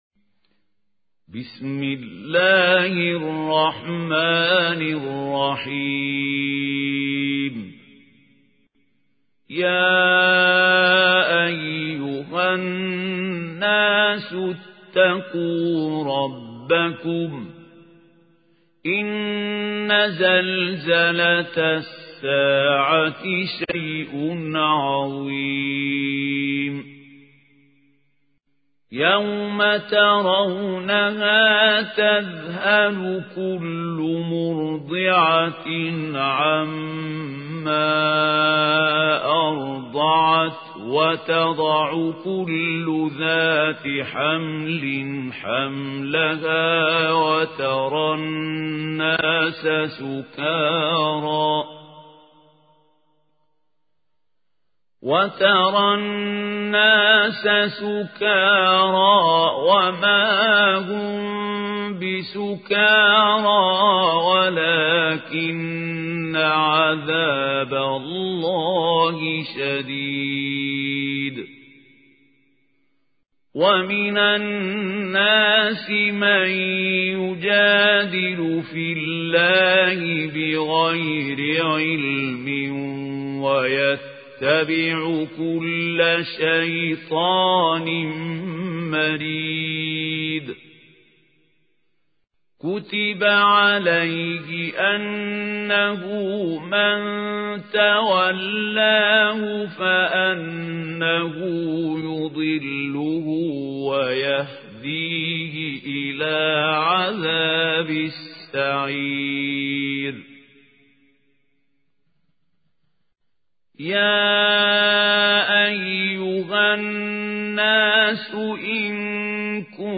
اسم التصنيف: المـكتبة الصــوتيه >> القرآن الكريم >> الشيخ خليل الحصري